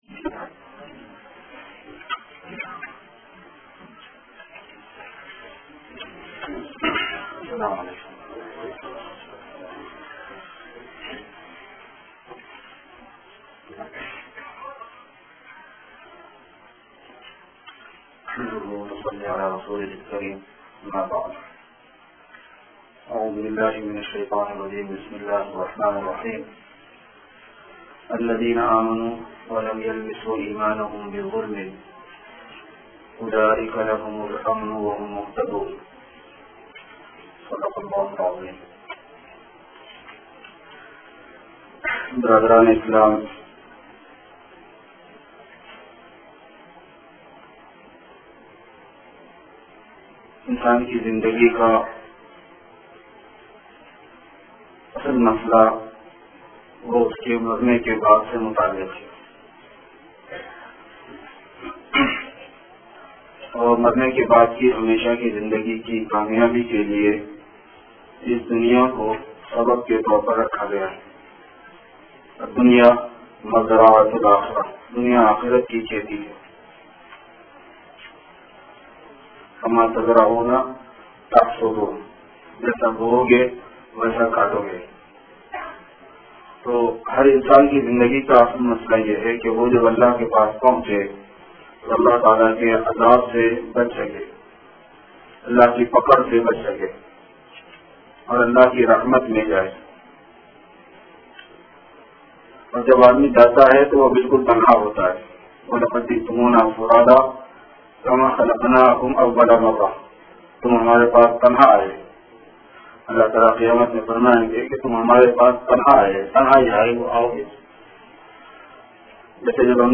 friday sermon - Page 18 of 25 - Silsila e Kamaliya